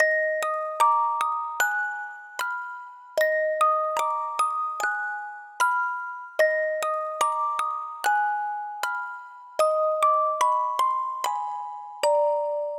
HORROR MUSIC BOX